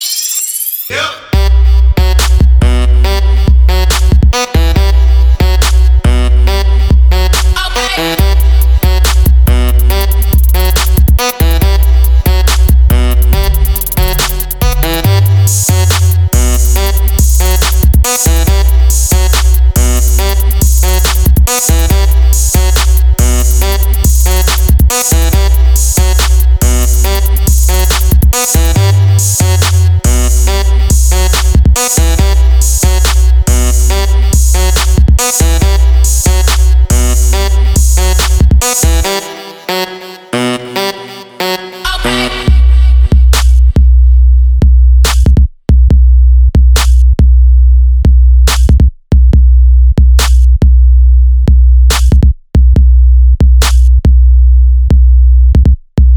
• Качество: 320, Stereo
remix
low bass